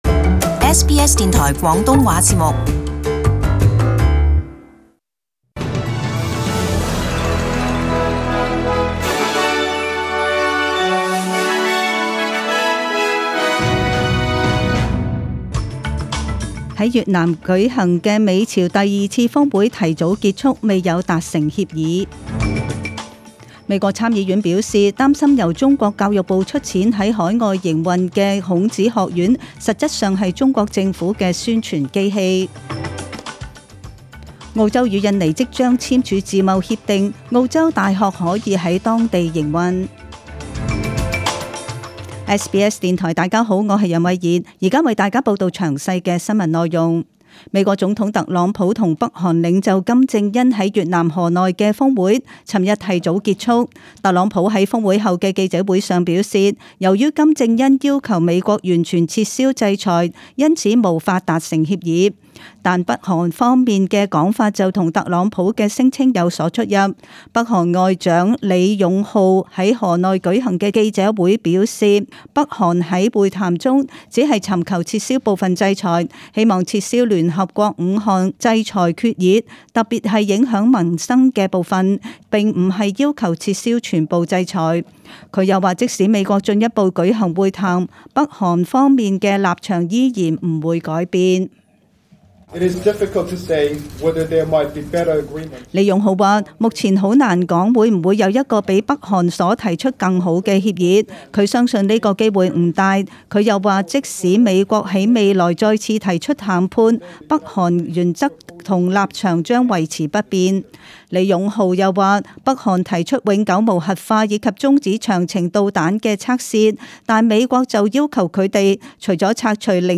Detailed morning news bulletin.